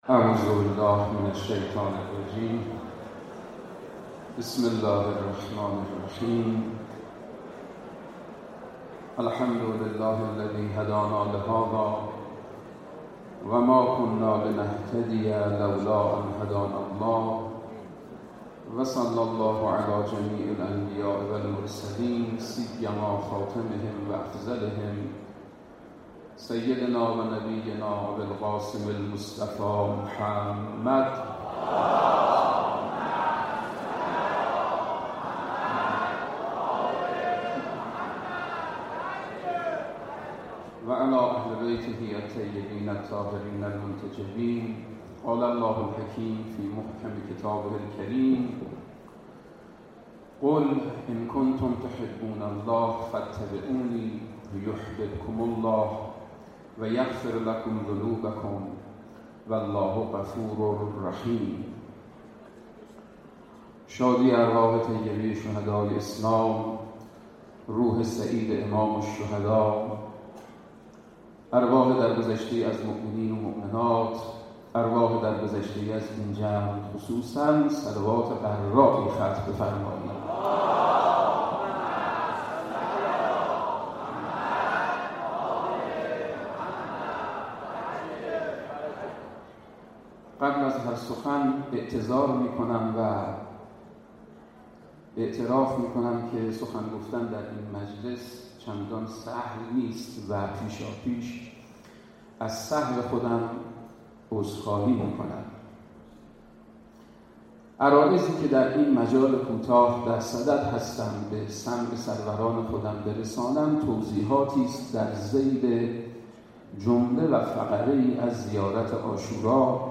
سخنرانی در روز اربعین در حسینیه امام خمینی